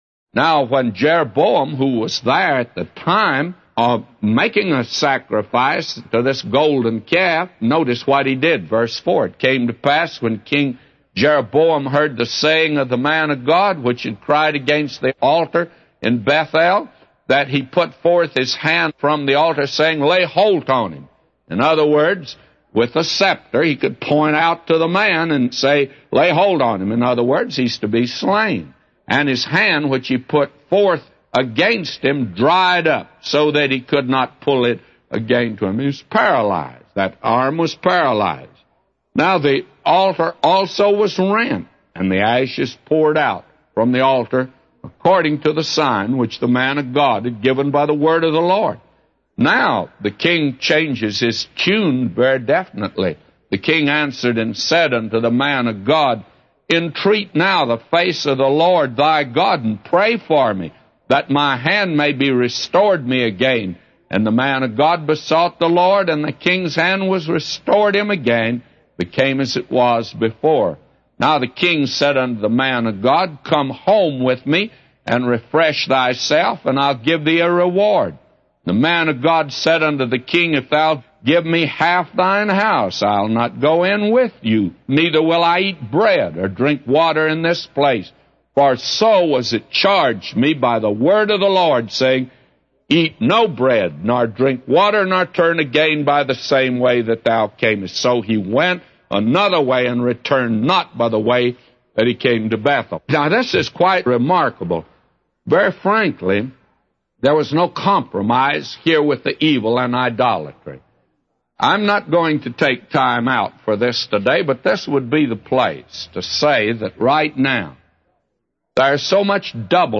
A Commentary By J Vernon MCgee For 1 Kings 13:1-999